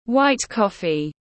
Cà phê trắng tiếng anh gọi là white coffee, phiên âm tiếng anh đọc là /waɪt ˈkɒfi/